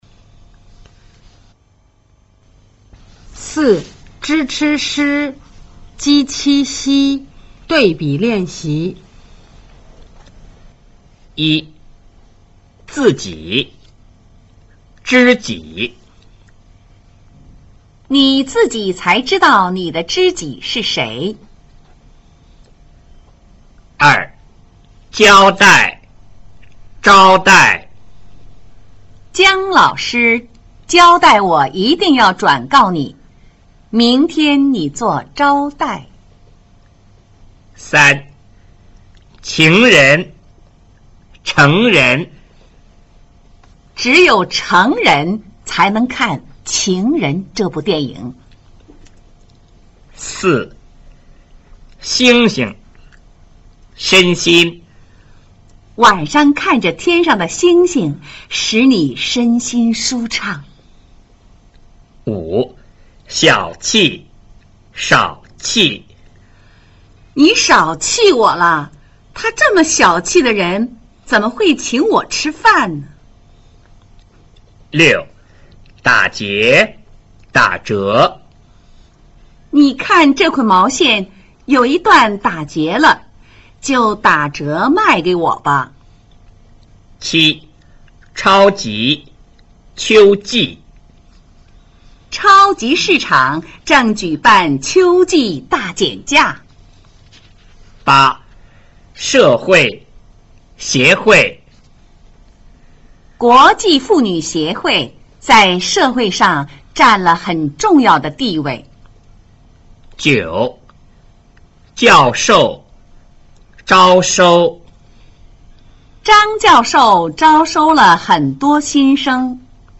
聲母 zh ch sh 翹舌音 和 j q x 舌面音的分辨
4. zh ch sh j q x 對比練習